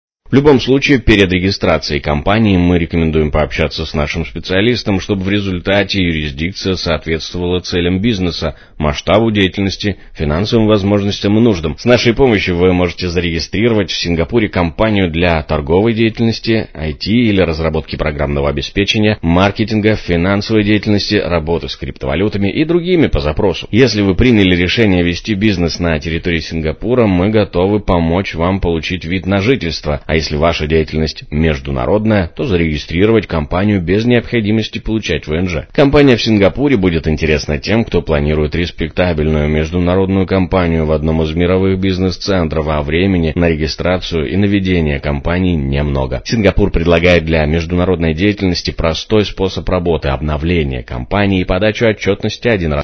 Multi-language_Text-to-Speech
man.mp3